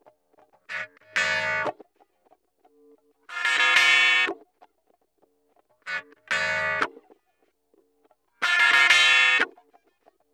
WRNG TWANG-R.wav